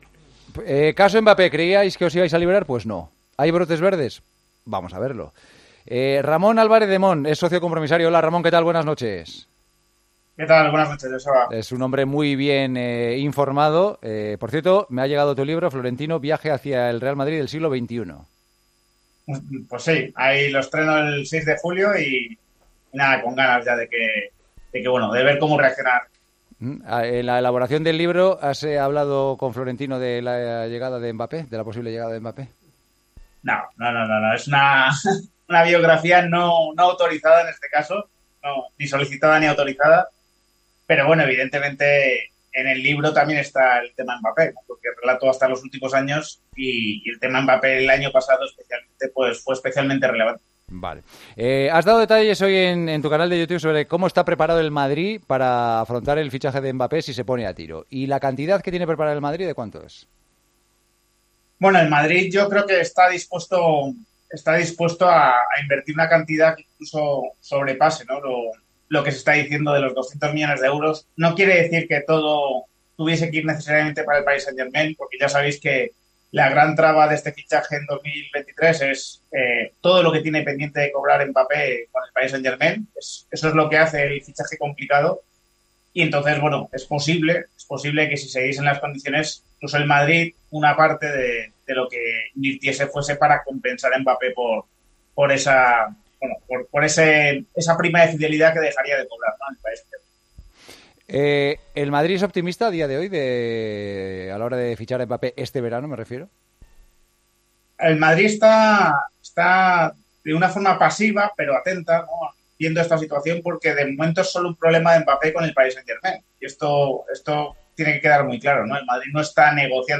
AUDIO: Hablamos con el socio compromisario del Real Madrid sobre la idea del equipo blanco para fichar a Mbappé.